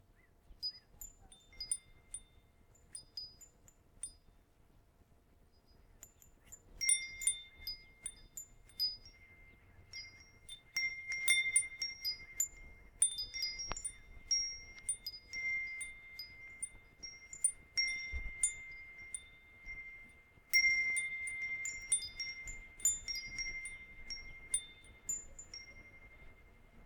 Wind chimes
Category 🌿 Nature
chime ching ding tinkle wind sound effect free sound royalty free Nature